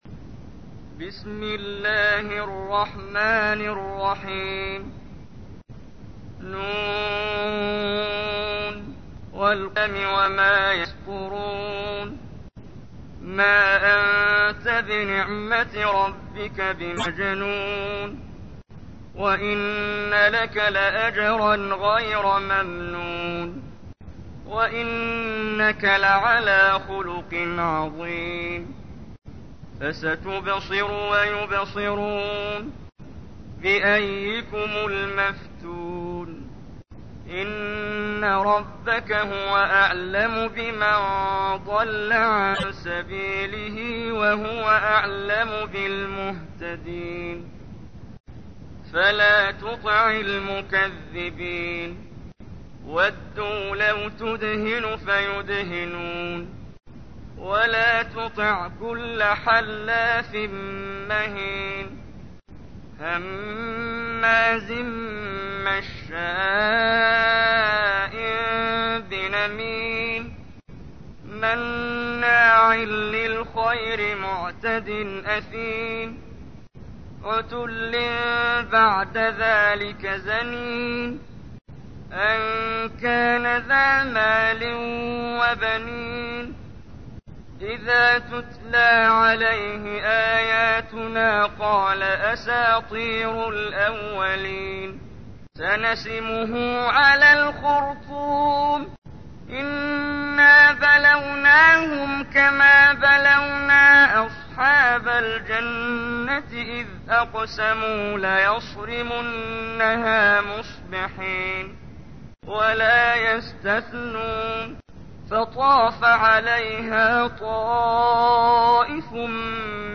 تحميل : 68. سورة القلم / القارئ محمد جبريل / القرآن الكريم / موقع يا حسين